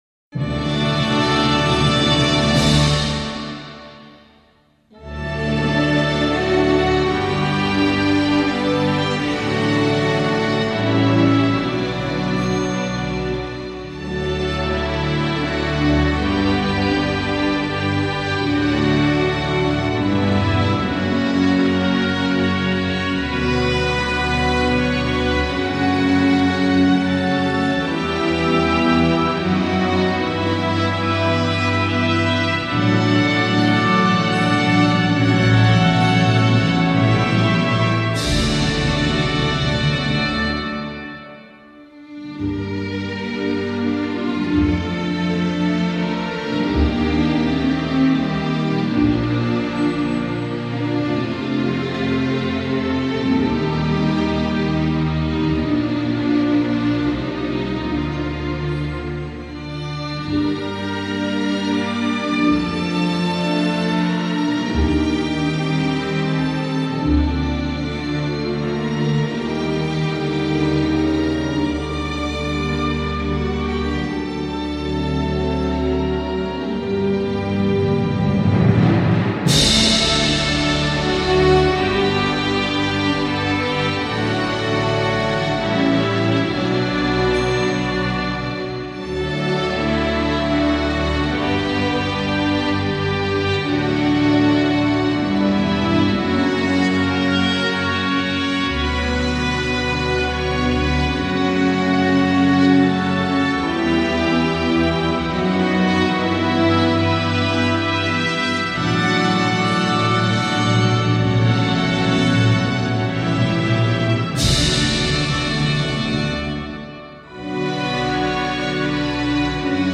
торжественную мелодию
инструментальная версия